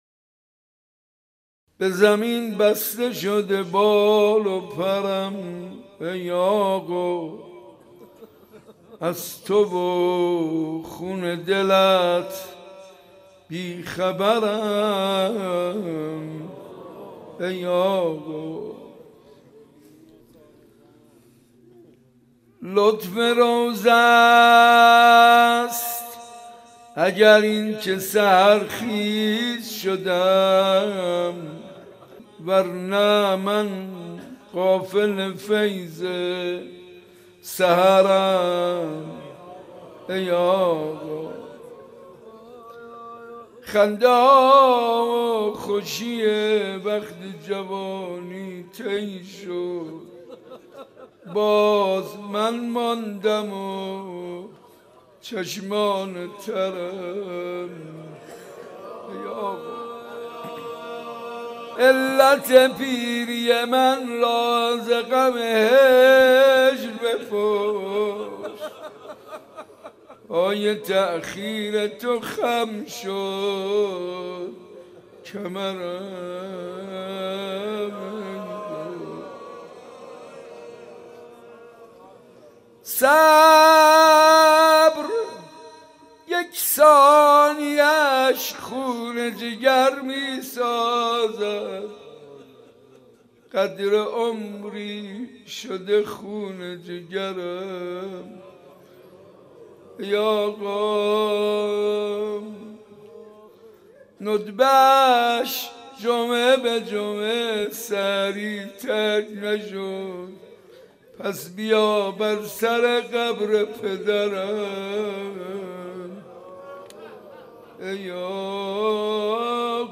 زیارت عاشورای صنف لباس فروش ها
مناجات با امام زمان